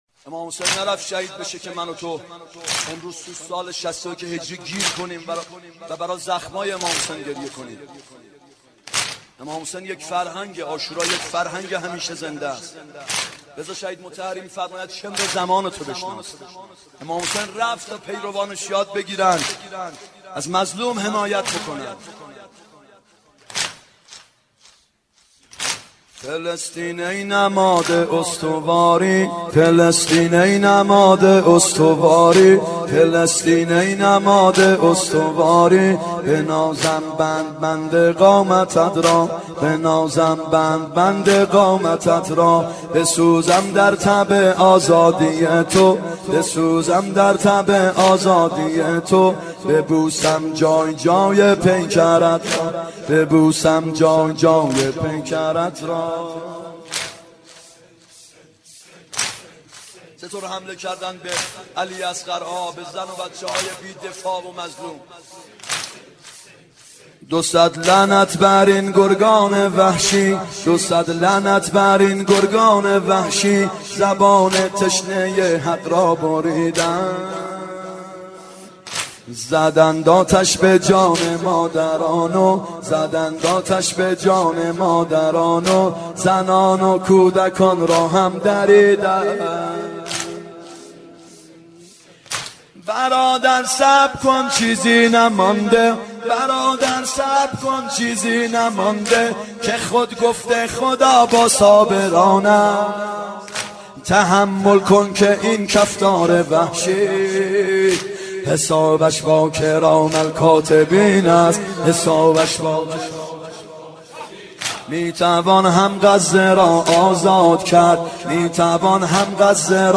محرم 91 ( هیأت یامهدی عج)